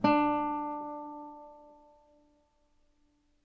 DET25-AdaptiveGuitar/Assets/AudioSources/Guitar Chords/Note_D.wav at main
Note_D.wav